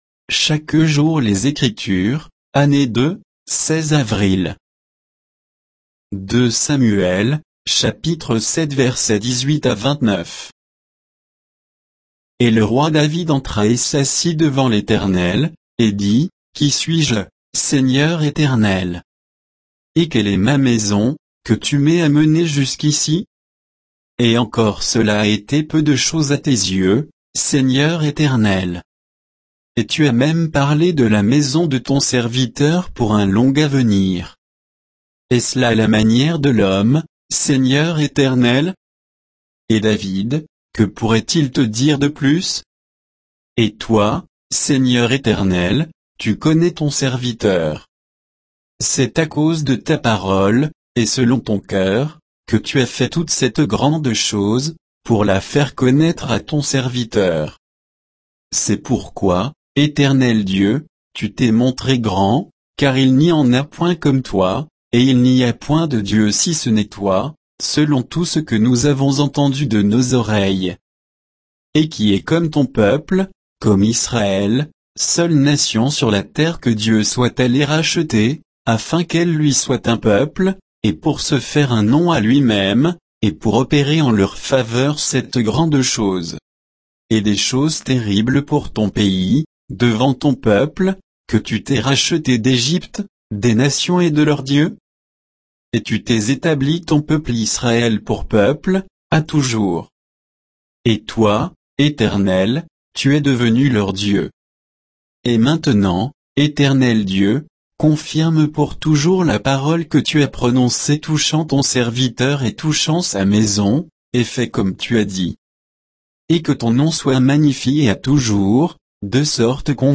Méditation quoditienne de Chaque jour les Écritures sur 2 Samuel 7, 18 à 29